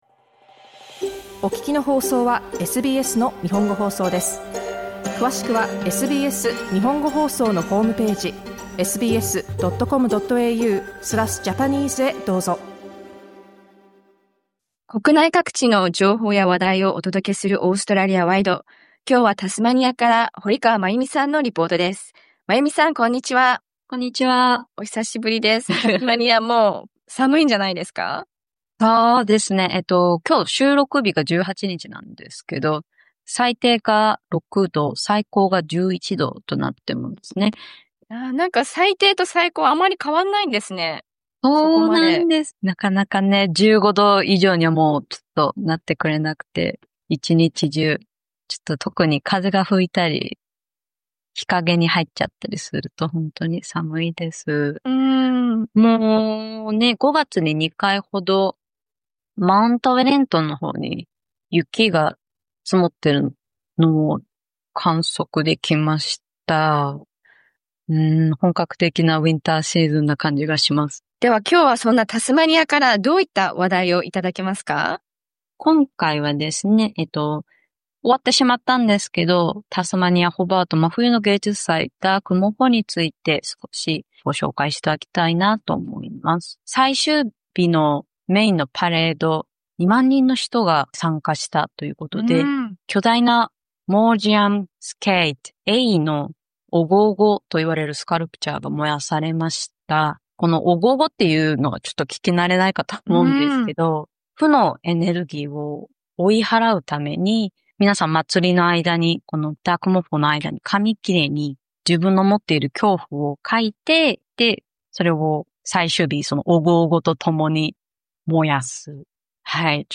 （オーストラリアワイド） アデレードから水事情と冬のフェスティバル（オーストラリアワイド） SBSの日本語放送は火木金の午後１時からSBS3で生放送！